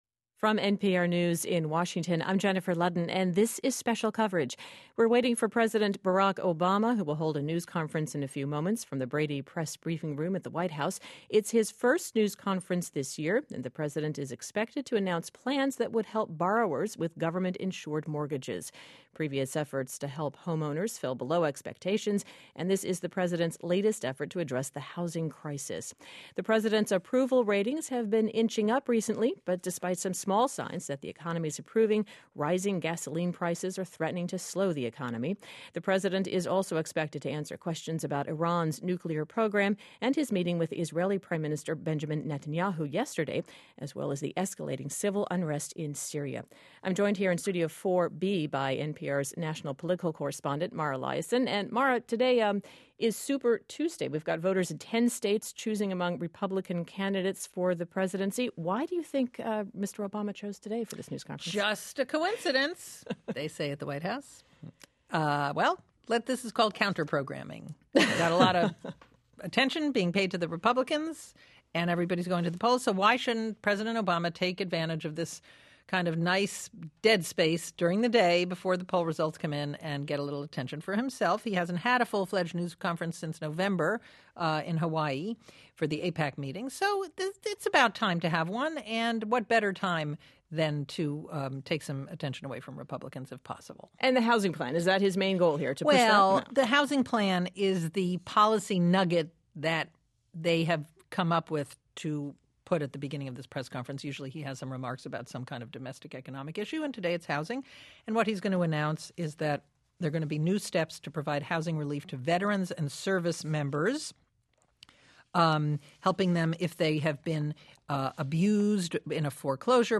In his first press conference of the year, Obama defended his decision not to intervene militarily in Iran and Syria.